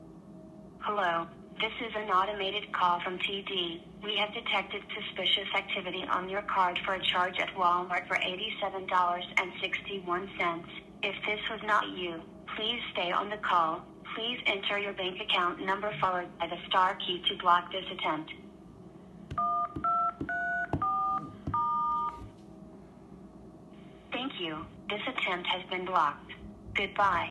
phish-scam-techniques-otp-bot-call.mp3